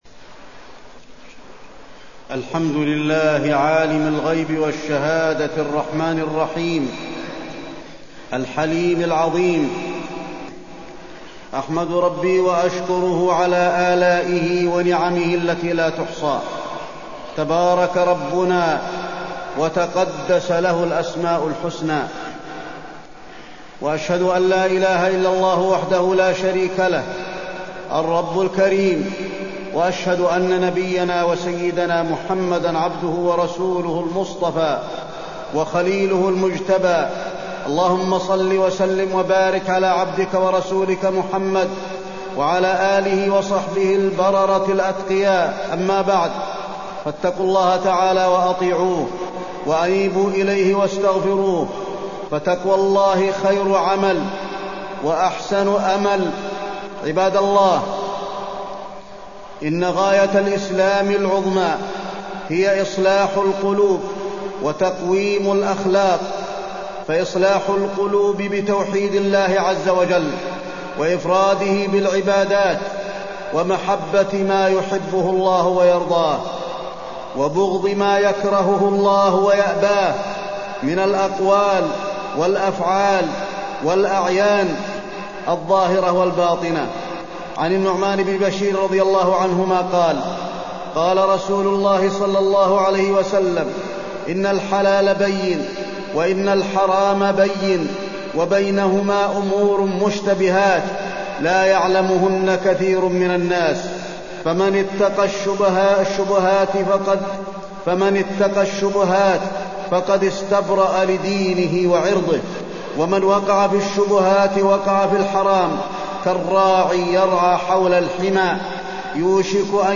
تاريخ النشر ١٨ محرم ١٤٢٤ هـ المكان: المسجد النبوي الشيخ: فضيلة الشيخ د. علي بن عبدالرحمن الحذيفي فضيلة الشيخ د. علي بن عبدالرحمن الحذيفي وحدة الأمة في الأزمان حكاماً ومحكومين